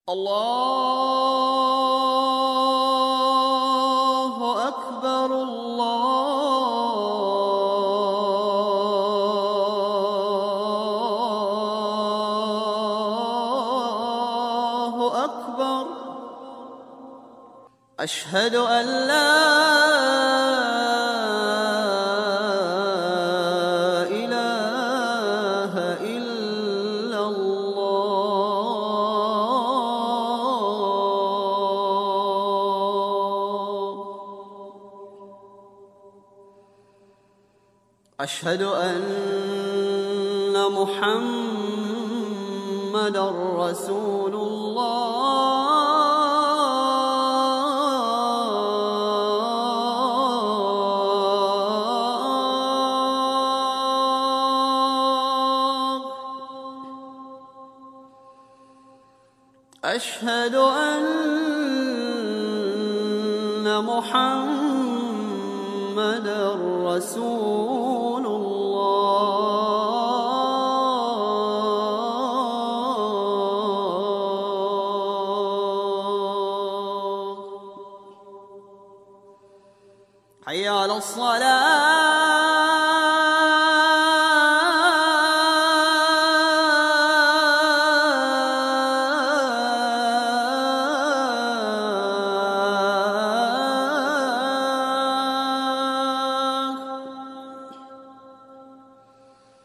На этой странице собраны записи Азана — проникновенного исламского призыва к молитве.
Звук азана мусульманской молитвы